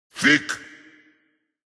Category: Games   Right: Personal